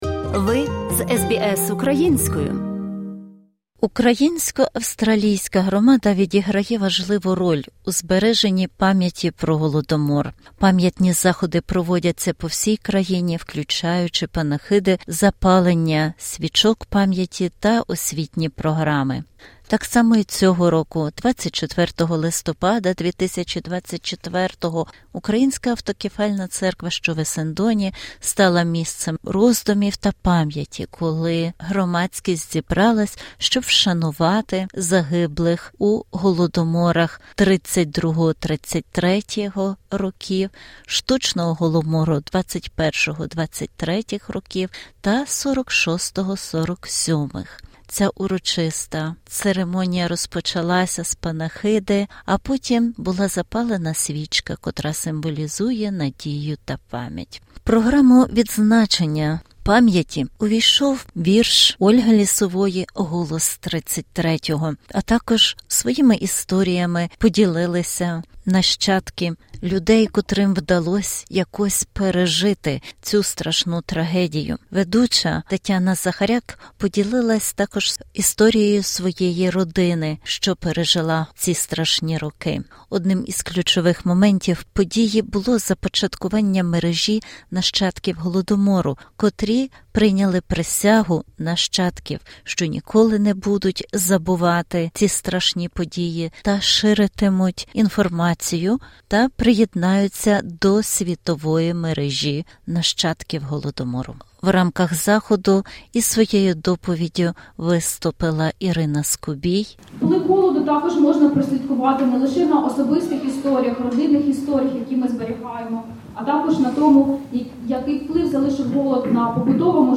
Цей репортаж документує урочисте вшанування пам’яті жертв Голодомору 1932-33 років та інших штучних голодоморів в Україні.